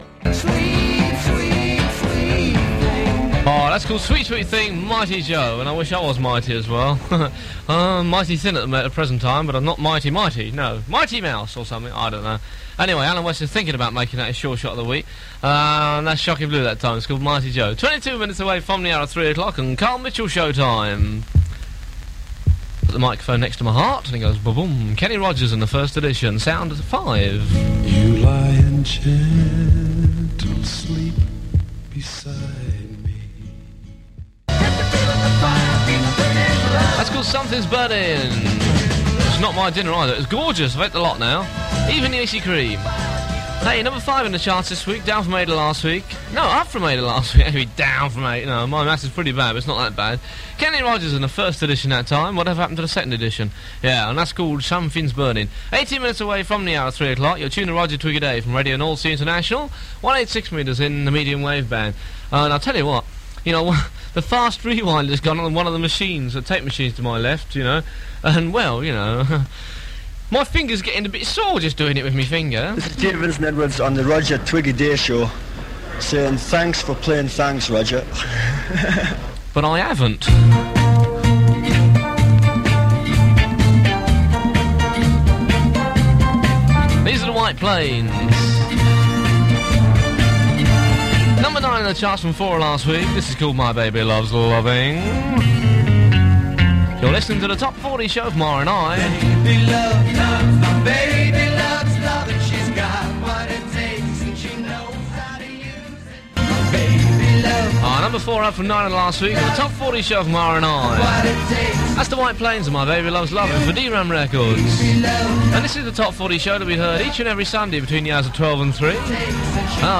So here again is “the sound of a young Europe” in crisp FM quality.